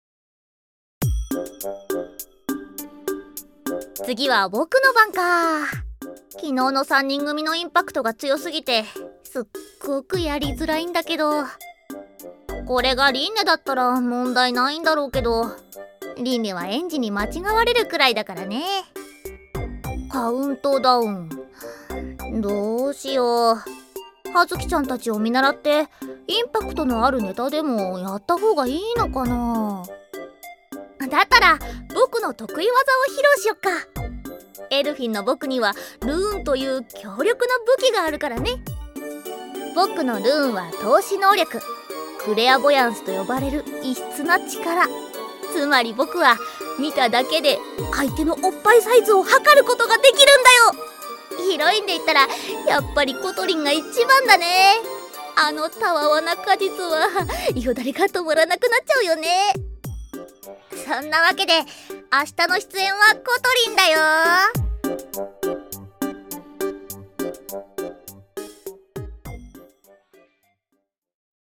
『アストラエアの白き永遠』 発売4日前カウントダウンボイス(ひなた)を公開